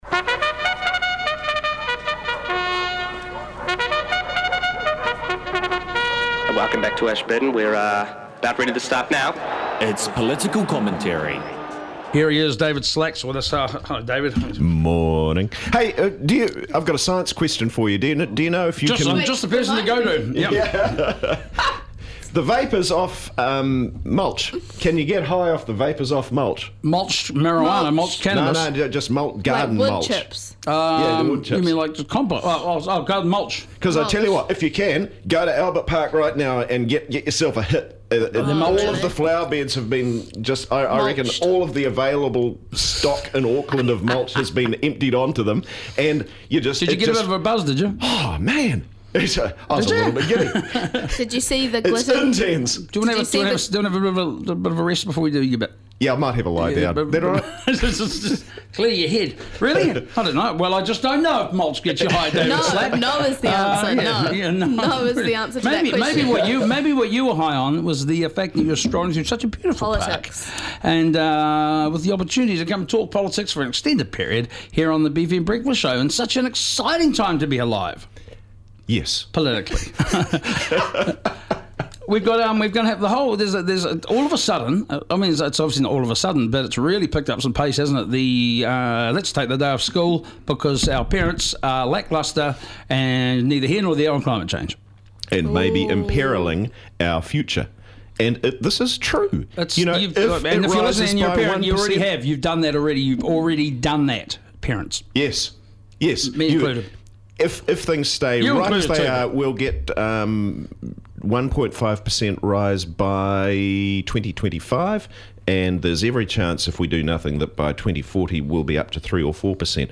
A rotating cast of the finest political minds offer up commentary on the big topics of the week.